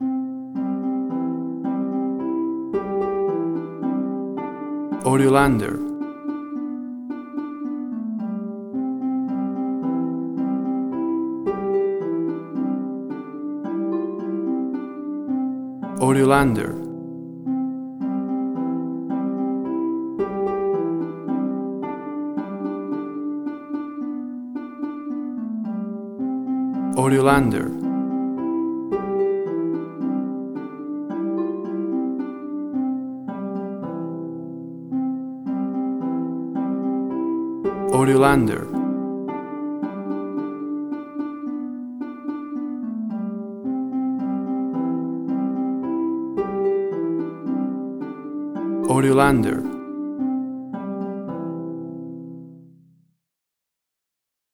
A beautiful Harp arrangement
Tempo (BPM): 110